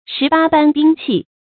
十八般兵器 注音： ㄕㄧˊ ㄅㄚ ㄅㄢ ㄅㄧㄥ ㄑㄧˋ 讀音讀法： 意思解釋： 本指刀槍劍戟等十八種古式兵器。